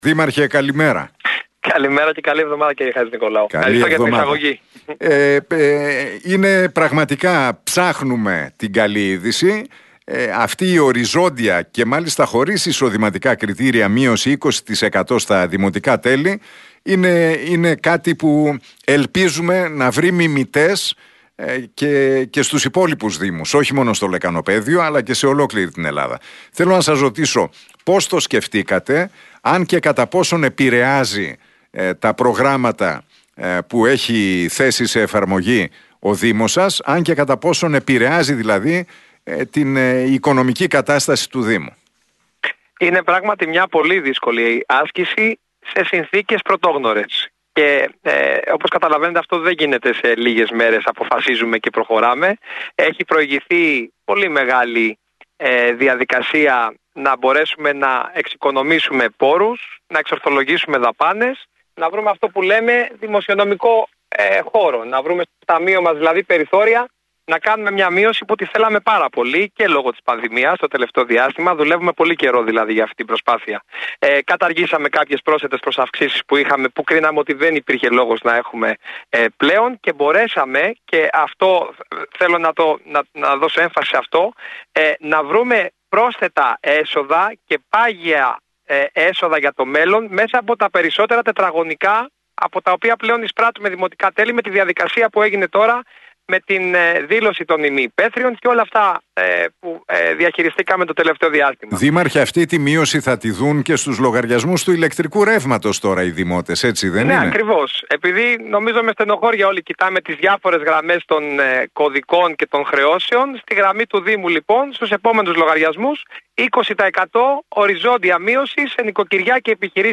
Για την απόφαση να εφαρμοστεί οριζόντια και χωρίς εισοδηματικά κριτήρια μείωση 20% στα δημοτικά τέλη στη Γλυφάδα μίλησε ο δήμαρχος Γιώργος Παπανικολάου, στον Realfm 97,8 και την εκπομπή του Νίκου Χατζηνικολάου.